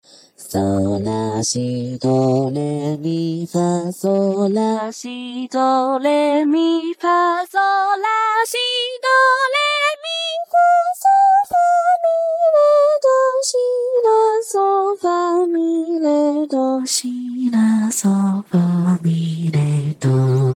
幸JPVCV_キレてる（kire+α）推薦        DL
power_F4        （例：- あ強）
whisper_A3     （例：- あ囁）